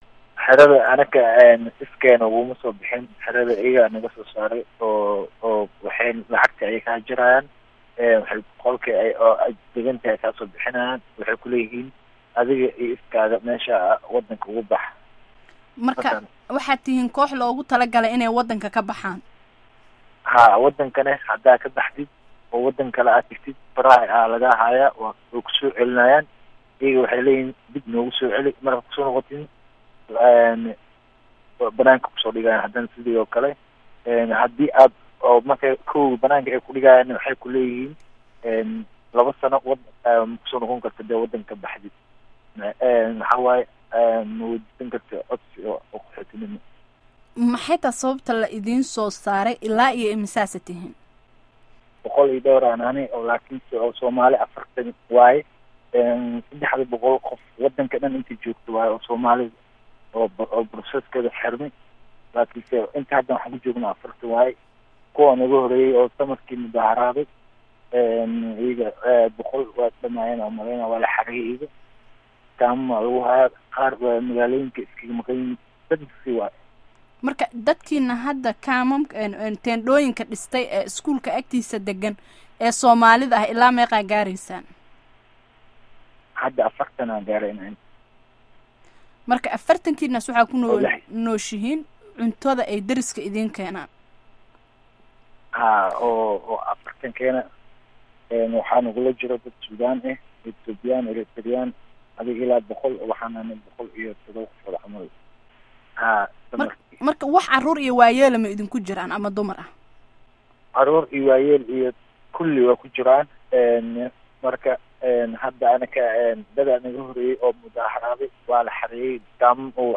Wareysiga Qaxootiga Holland